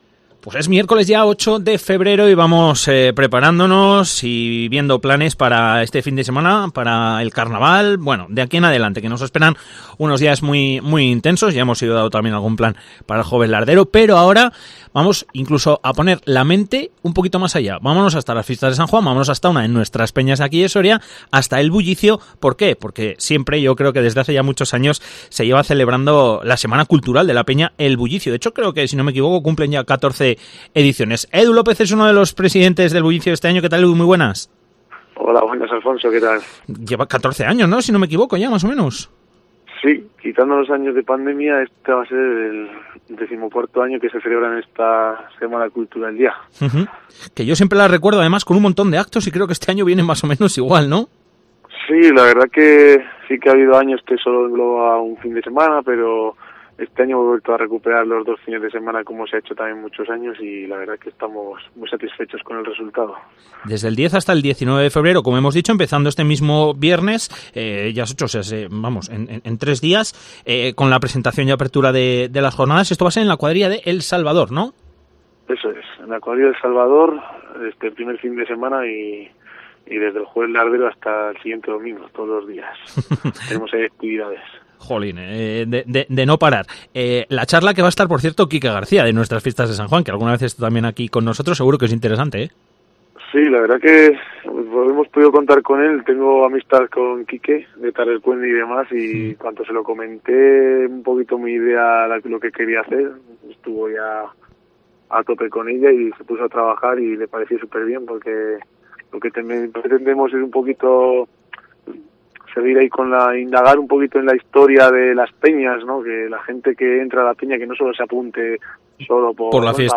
SEMANA CULTURAL BULLICIO: Entrevista